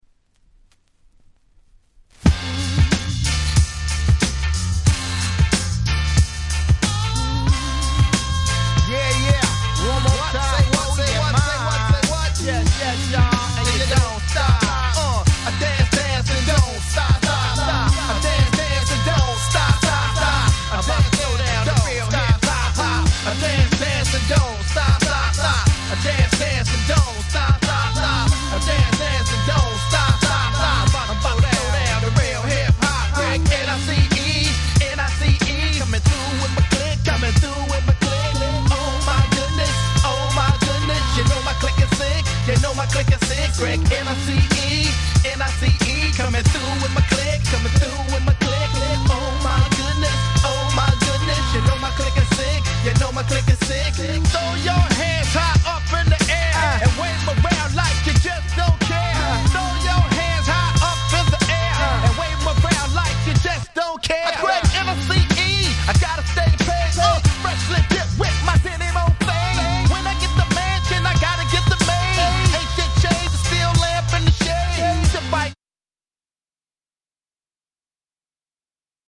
キャッチーなBeatにお馴染みの彼のFlowがこだまする超絶Party Hip Hopです！！